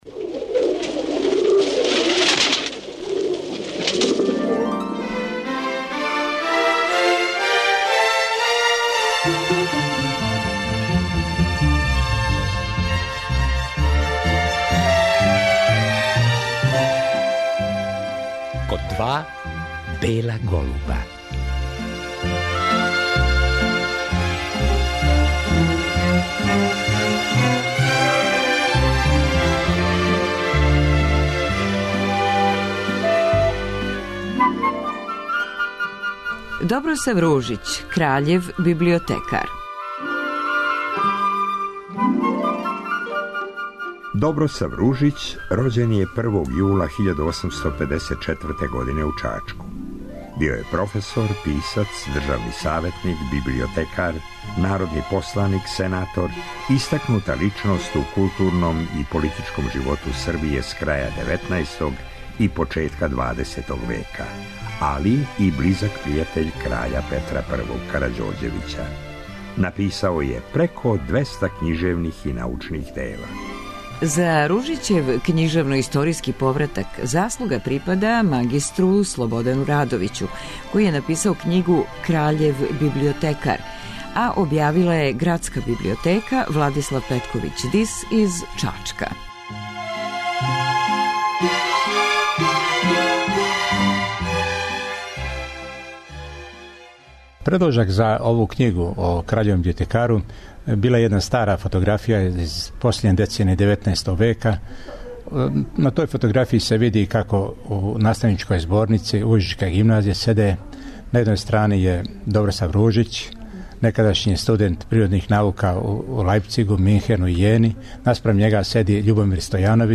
У вечерашњој емисији прочитаћемо још неколико одломака из књиге, посебно онај о Ружићевом открићу „Мирослављевог јеванђеља", којем се траг изгубио у ноћи Мајског преврата.